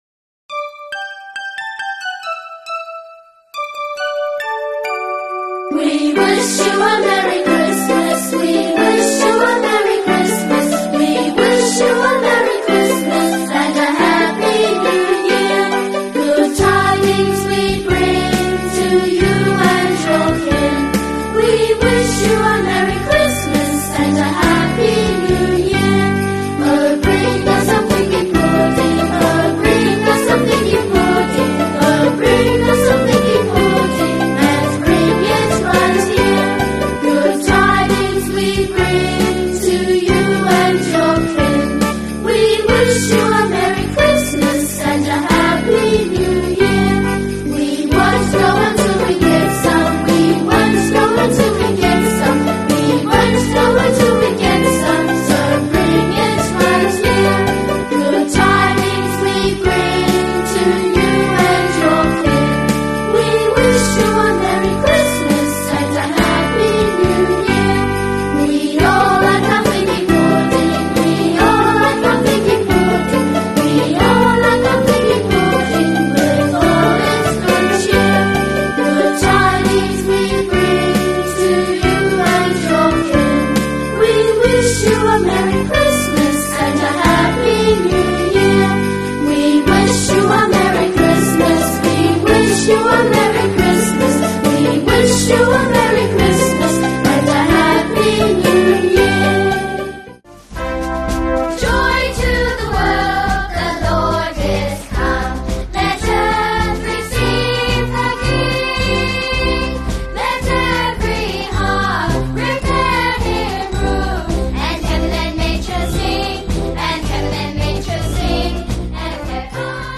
آهنگ شاد کریسمس